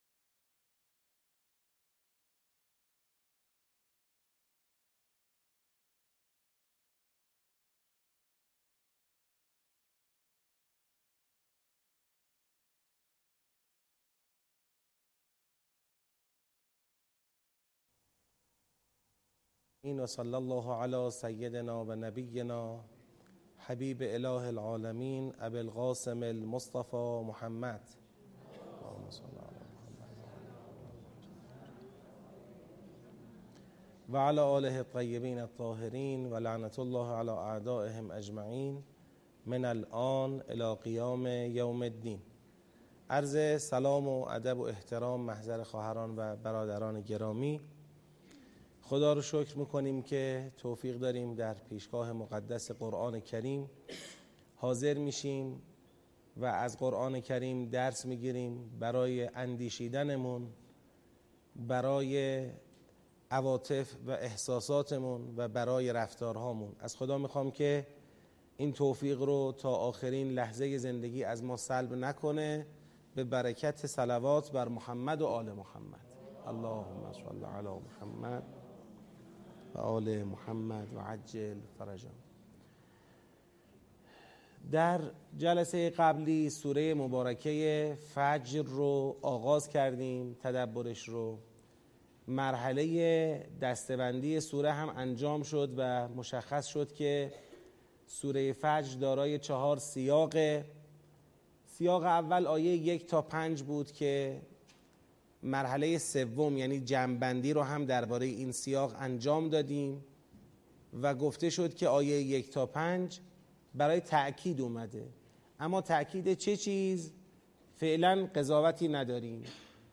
با حضور ۲۵۰ نفر از قرآن آموزان در مسجد پیامبر اعظم (ص) شهرک شهید محلاتی تهران آغاز شد.